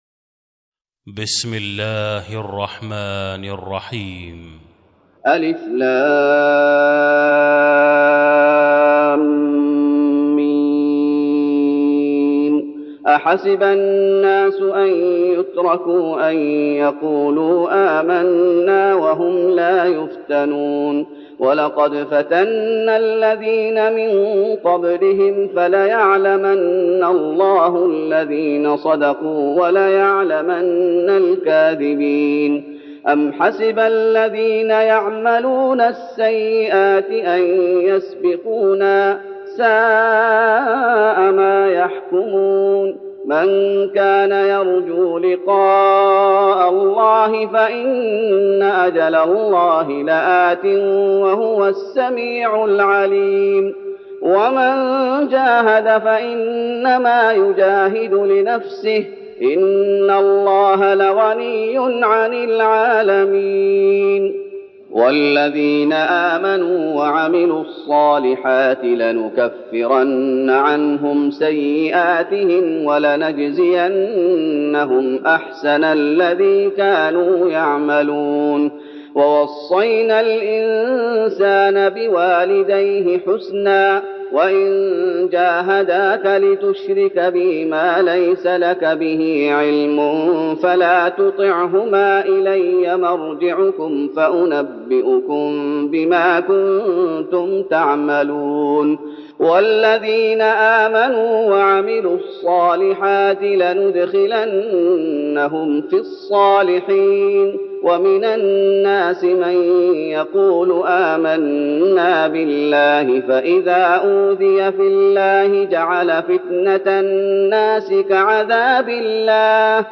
تراويح رمضان 1412هـ من سور العنكبوت (1-44) Taraweeh Ramadan 1412H from Surah Al-Ankaboot > تراويح الشيخ محمد أيوب بالنبوي 1412 🕌 > التراويح - تلاوات الحرمين